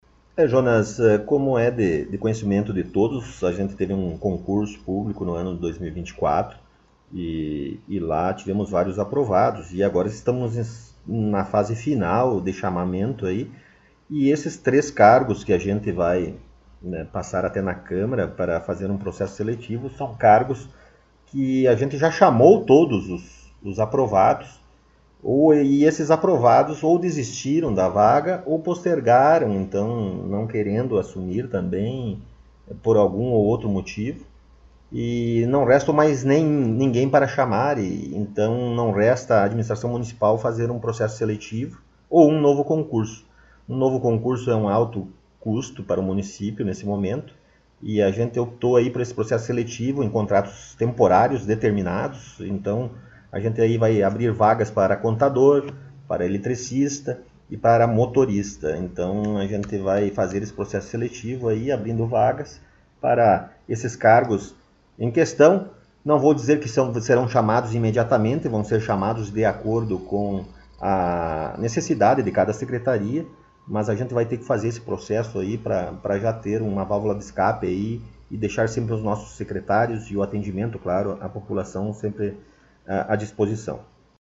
Prefeito Rodrigo Sartori concedeu entrevista
Na última semana, mais uma vez, o jornal Colorado em Foco teve a oportunidade de entrevistar o prefeito Rodrigo Sartori em seu gabinete na Prefeitura Municipal.